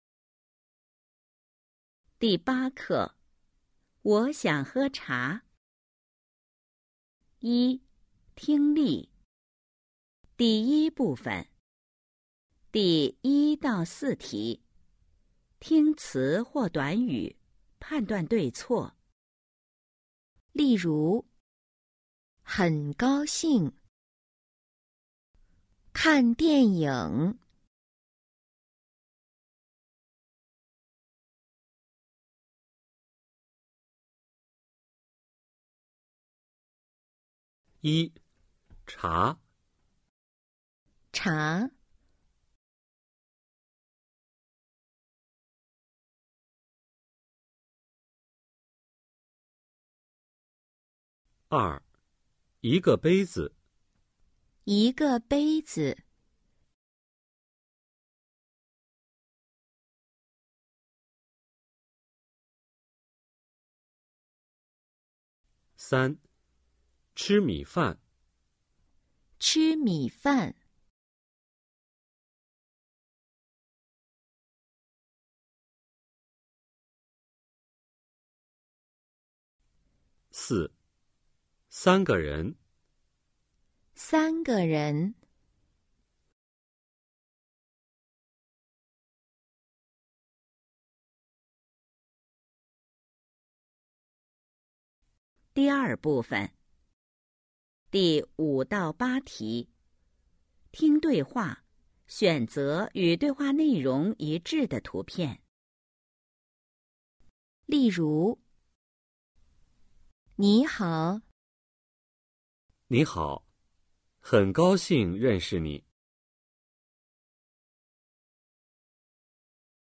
一、听力 Phần nghe 🎧 08-1